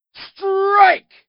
strike2.wav